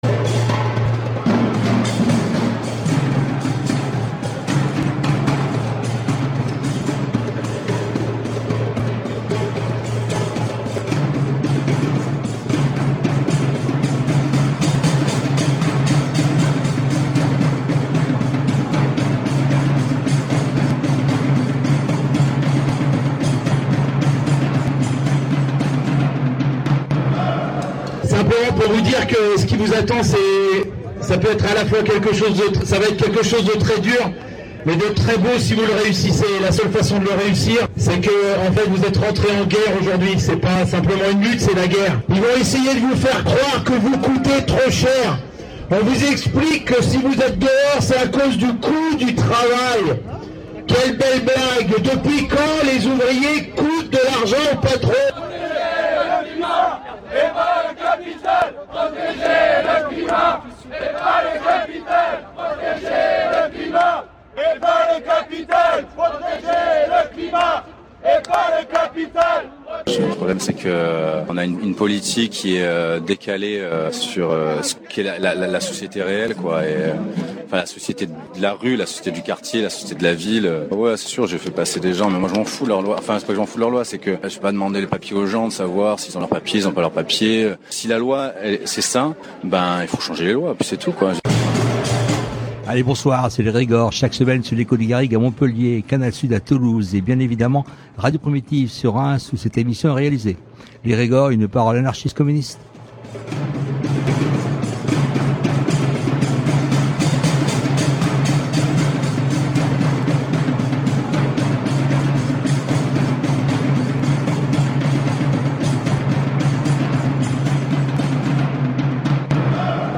Elles étaient là le 8 novembre 2025, au cirque électrique, dans le 20ᵉ arrondissement de Paris, pour contrer le discours dominant, mettre en commun leurs enquêtes et raconter leurs luttes et les soirées de mobilisations. C’est l’écoute d’une grande partie de cette première table ronde que nous vous proposons aujourd’hui. classé dans : société Derniers podcasts Yeah yeah yeah !